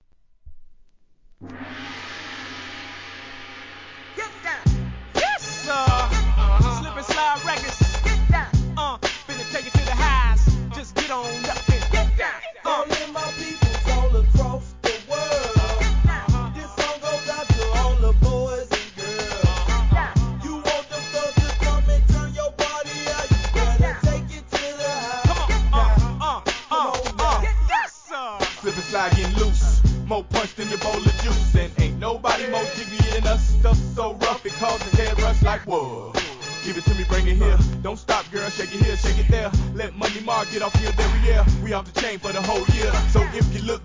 HIP HOP/R&B
甲高いホーンが鳴る陽気なトラックにマイアミらしさが充満した一曲！